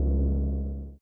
engine-out.ogg